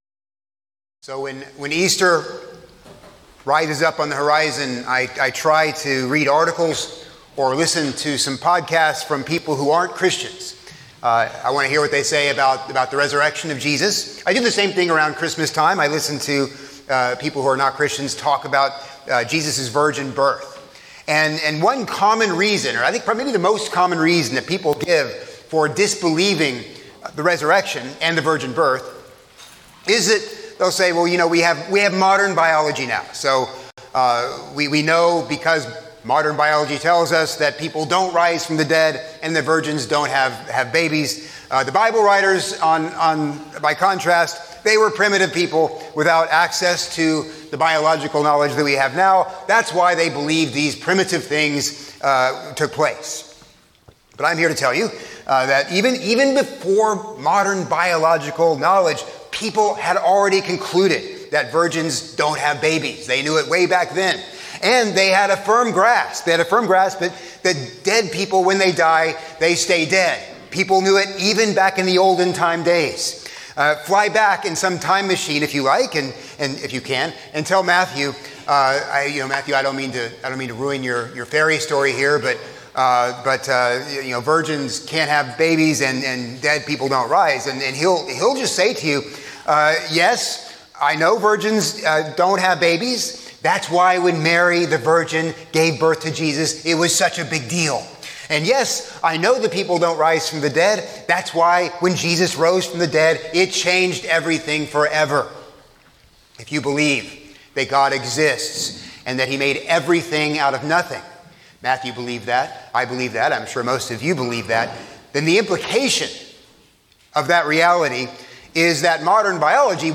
from April 5, 2026 (Easter Sunday)